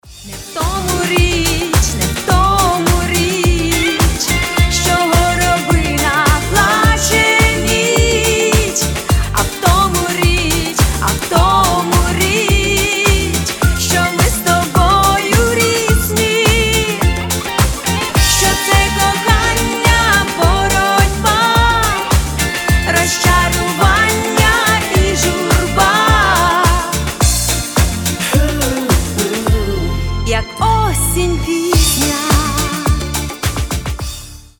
• Качество: 320, Stereo
эстрадные